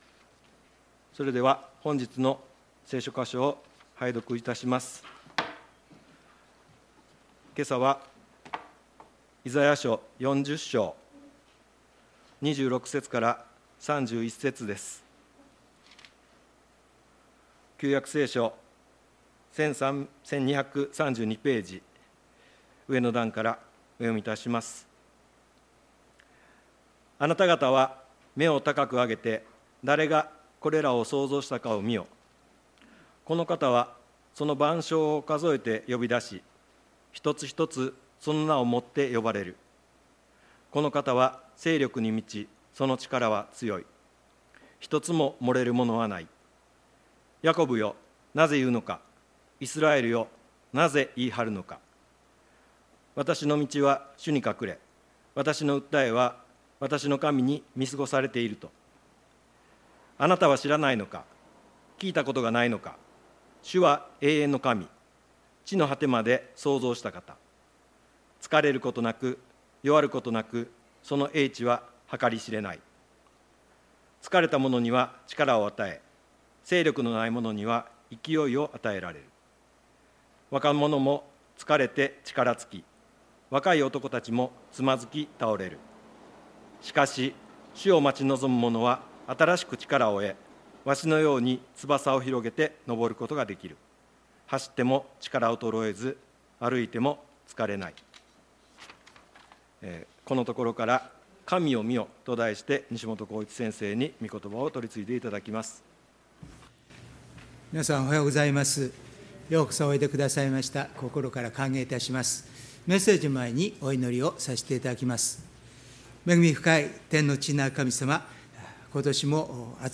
礼拝メッセージ「神を見よ」│日本イエス・キリスト教団 柏 原 教 会